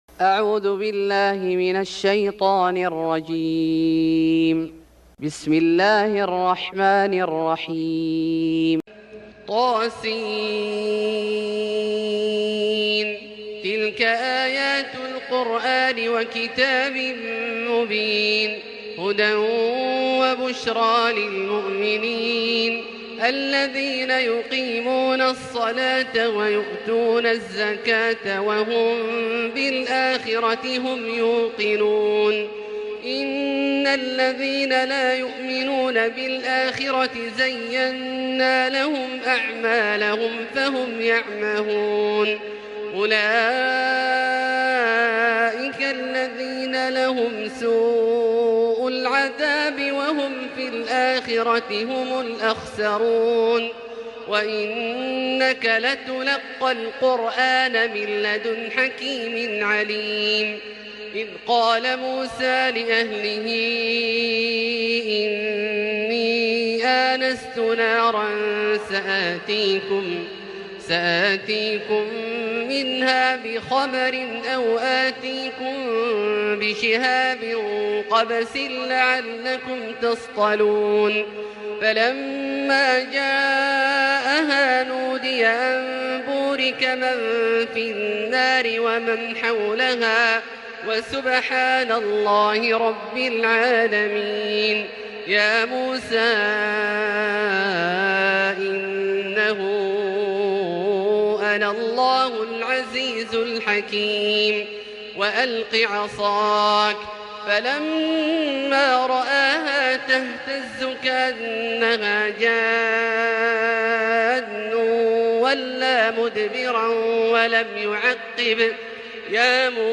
سورة النمل Surat An-Naml > مصحف الشيخ عبدالله الجهني من الحرم المكي > المصحف - تلاوات الحرمين